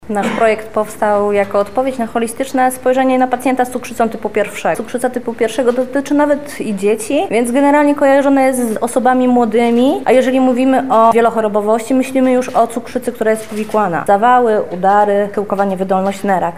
Dziś (22.01) odbyła się konferencja prasowa poświęcona nowoczesnej farmakoterapii z zaawansowaną technologią diabetologiczną.
konferencja-medyczny1.mp3